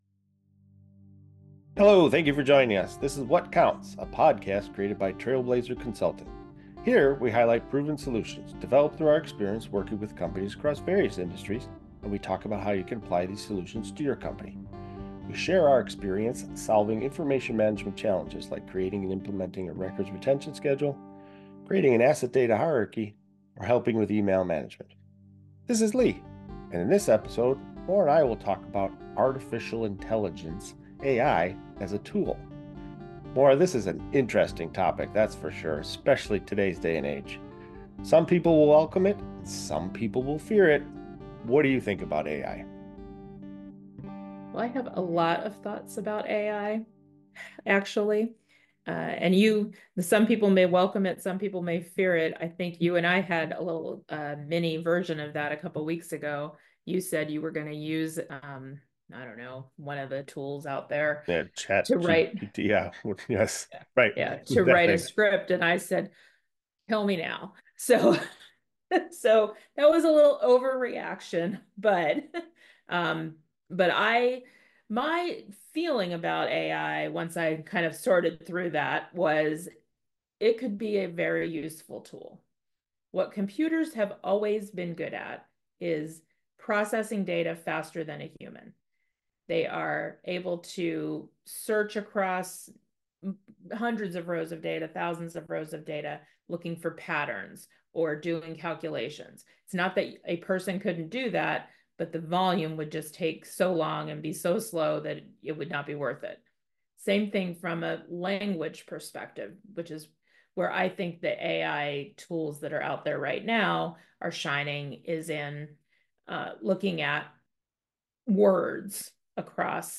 Each episode contains important information gained through our experience working with companies across various industries and we talk about how you can apply this experience to your company.